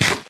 eat3.ogg